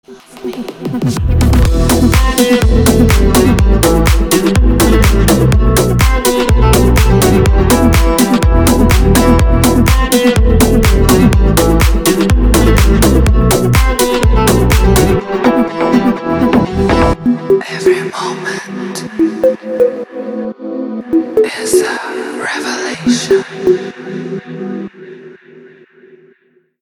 Танцевальные рингтоны
заводные приятные
Deep House восточные мотивы
шепот